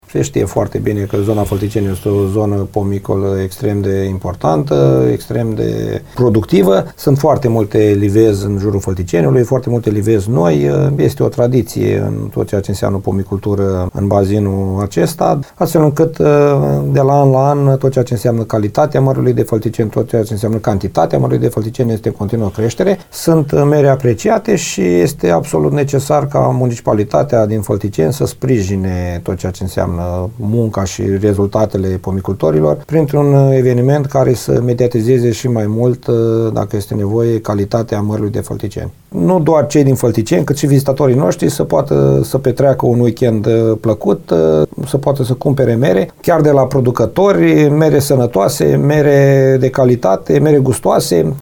Primarul CĂTĂLIN COMAN a declarat că la Târgul Mărului și-au anunțat prezența ministrul Agriculturii FLORIN BARBU și directorul general APIA, CORNEL TURCESCU.